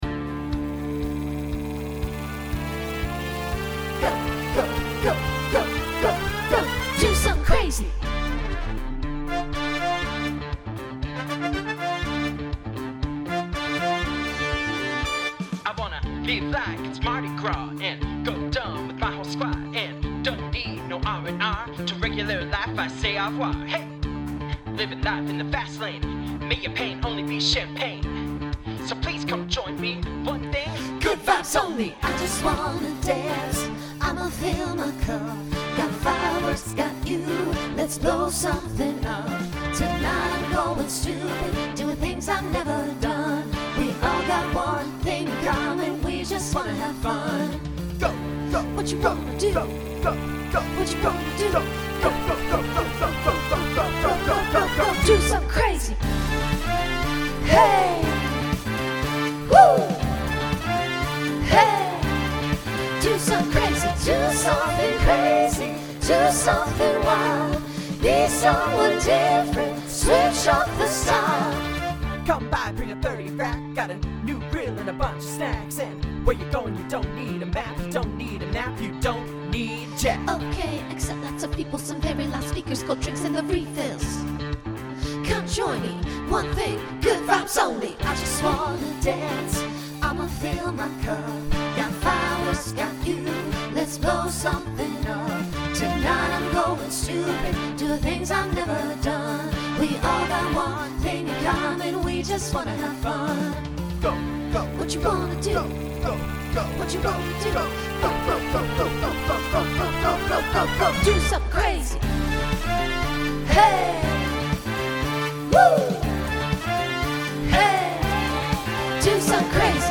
Pop/Dance , Rock Instrumental combo
Voicing SATB